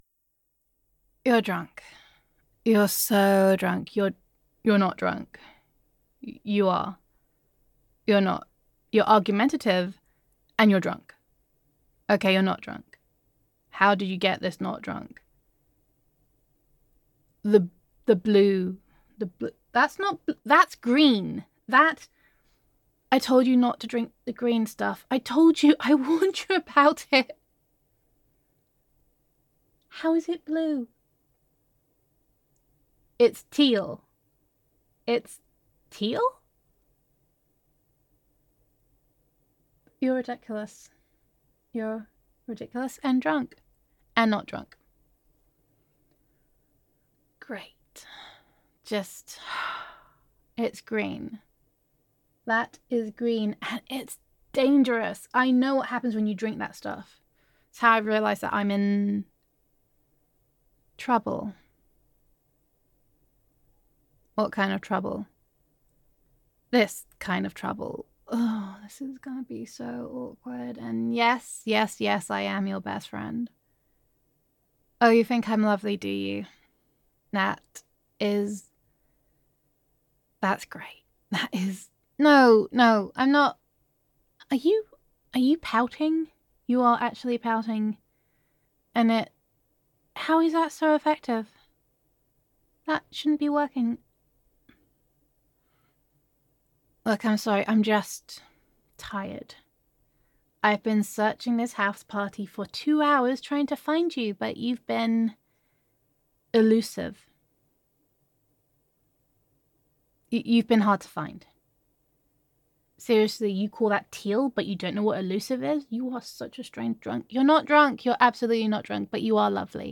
[F4A] Can You Keep a Secret?
[Best Friend Roleplay]